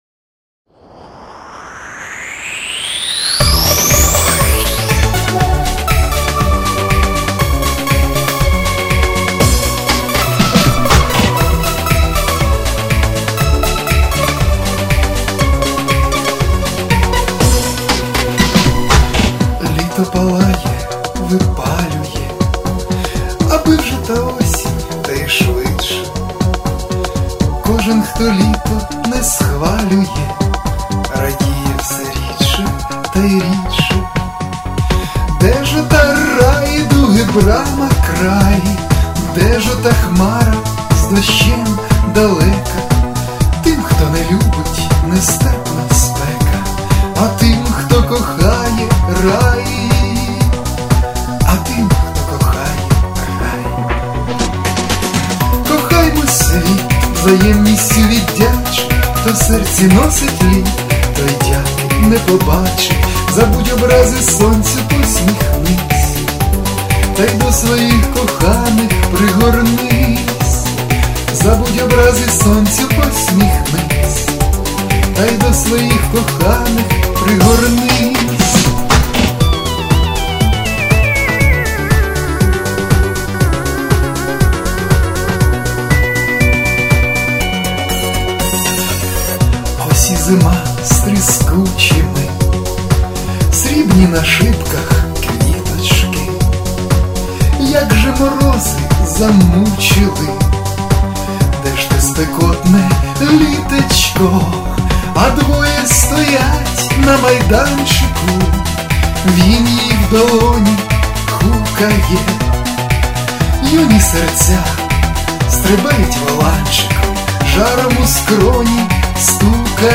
Друга, вдосконалена версія пісні.
Рубрика: Поезія, Авторська пісня